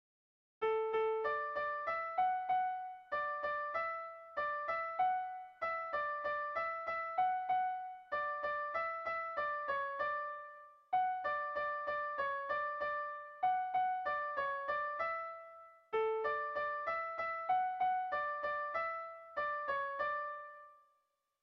Zortziko txikia (hg) / Lau puntuko txikia (ip)
ABDB